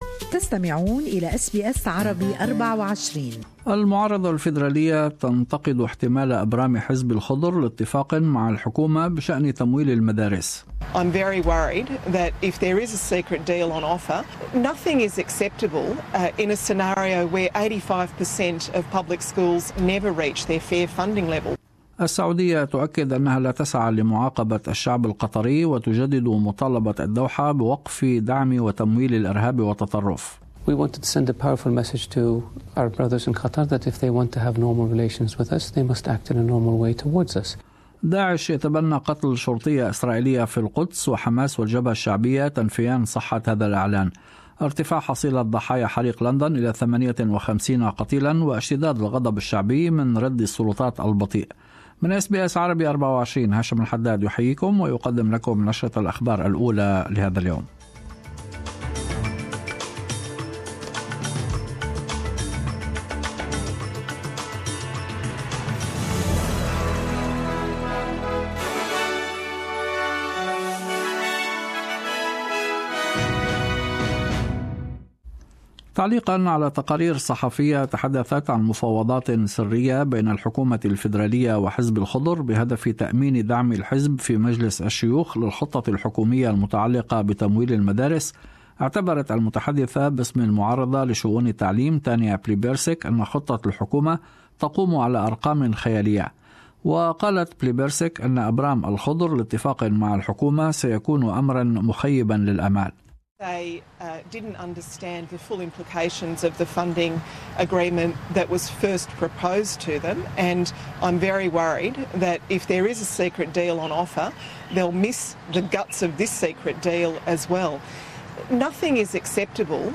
Morning news bulletin brought you in Arabic from SBS Arabic 24.